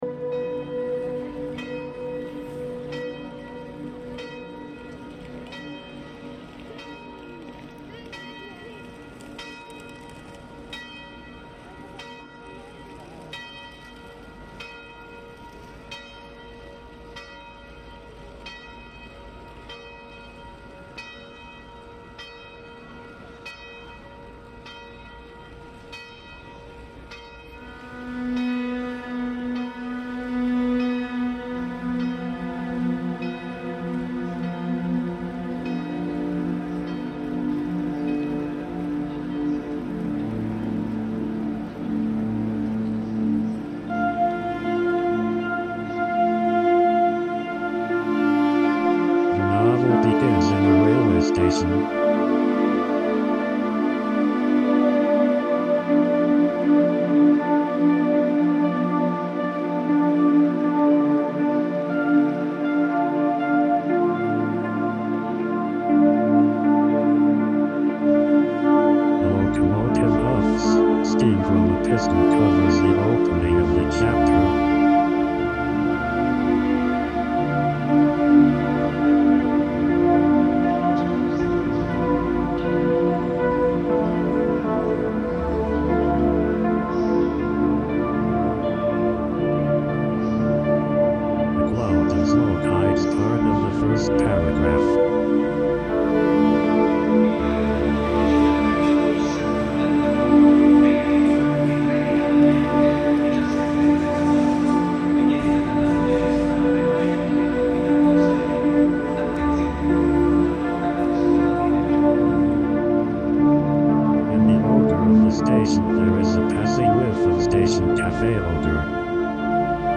Venice Santa Lucia station on New Year's Eve reimagined